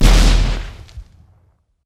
sk04_explosion.wav